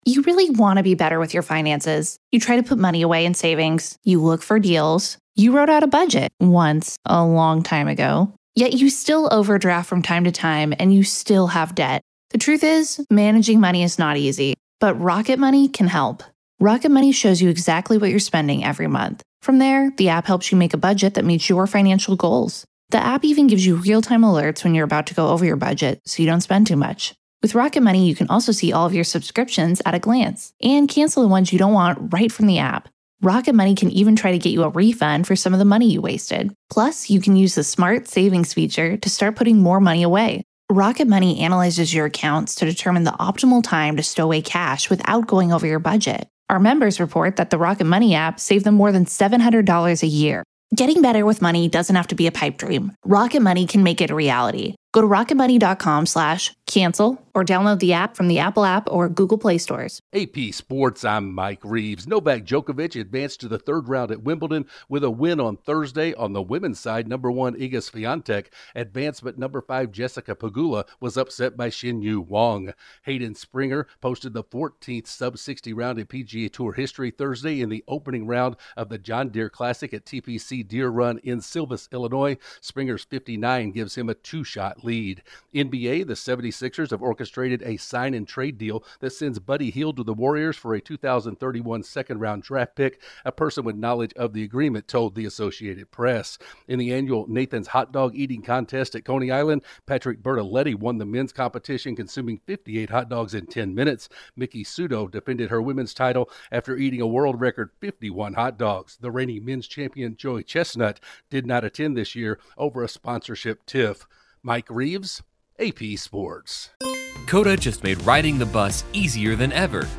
Sports News